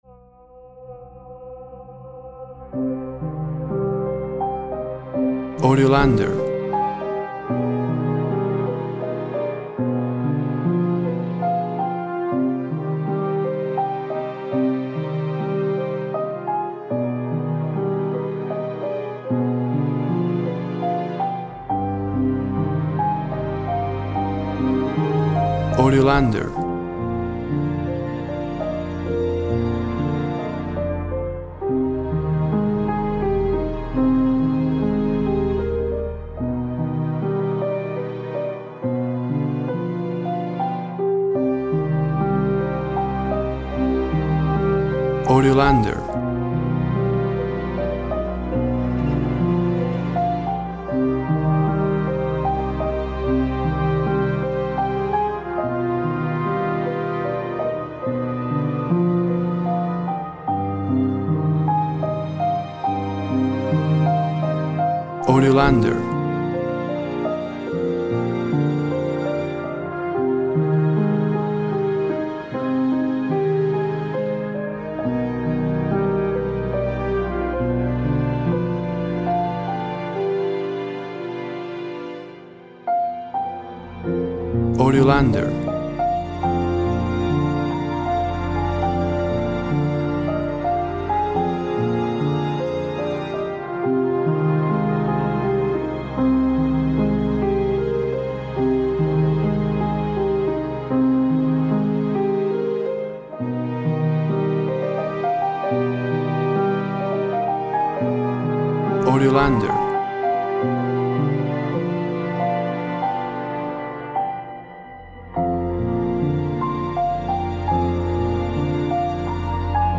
A sad, romantic piano ballad.
Tempo (BPM) 75